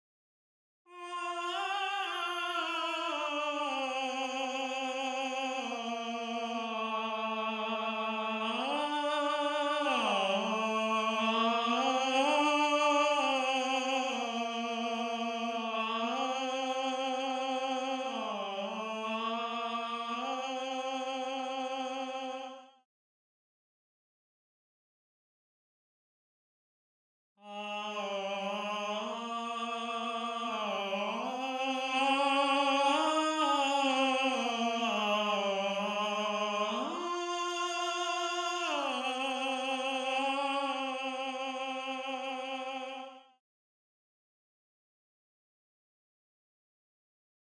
Voice 5 (Tenor/Tenor)
gallon-v8sp5-22-Tenor_0.mp3